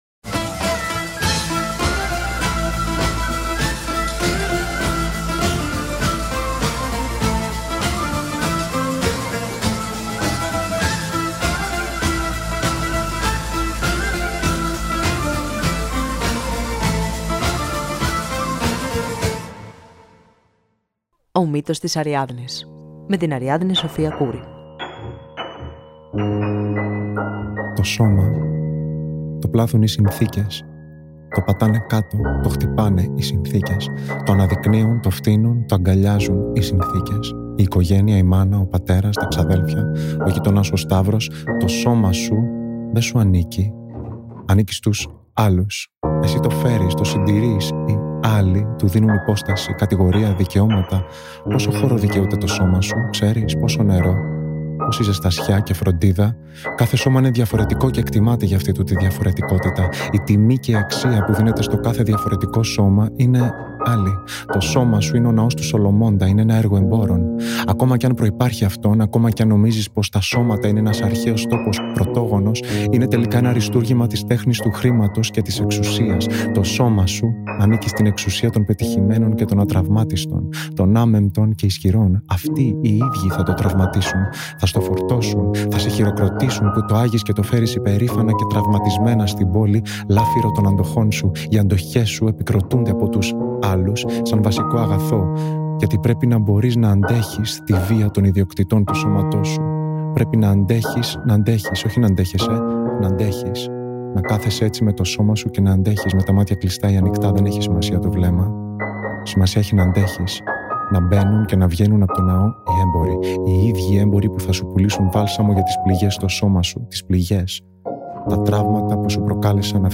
*Στο επεισόδιο ακούγεται πρωτότυπη μουσική